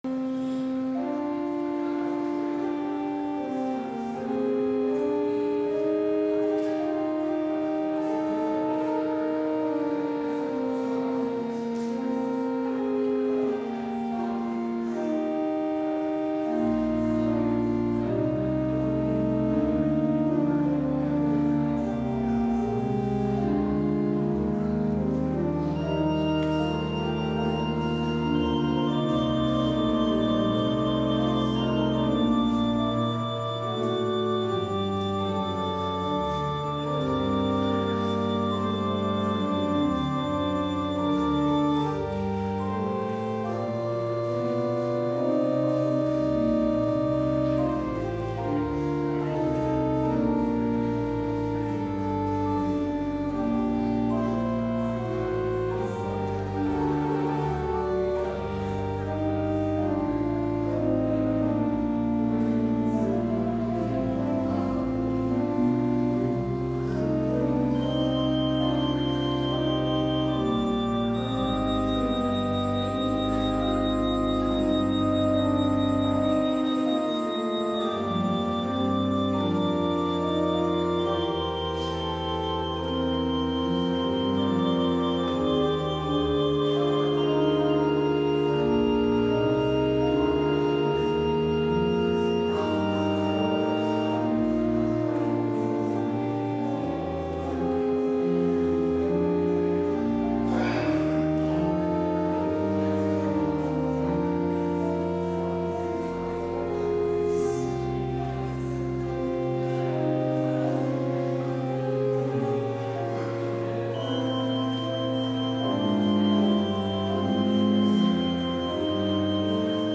Complete service audio for Chapel - January 21, 2020